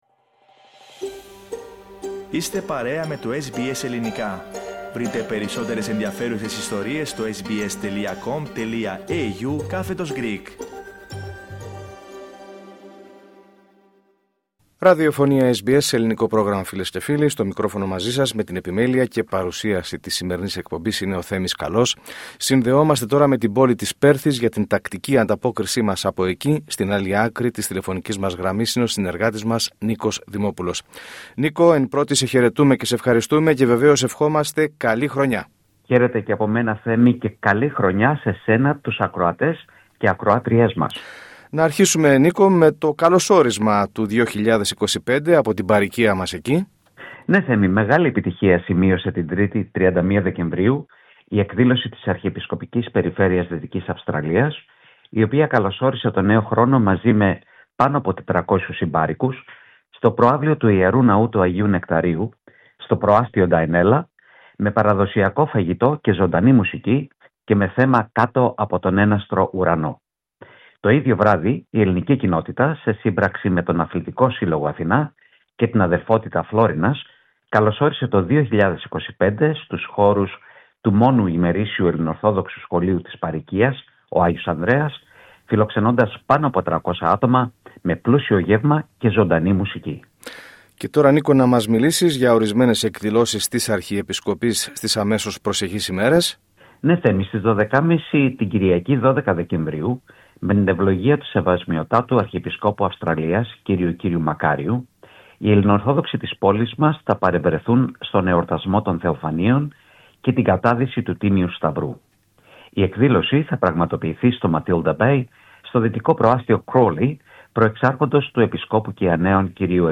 Ορισμένες εκδηλώσεις για την έλευση του νέου έτους καθώς και ο επικείμενος εορτασμός των Θεοφανίων, είναι τα θέματα της εβδομαδιαίας ανταπόκρισης από την Πέρθη, της πρώτης για το νέο έτος.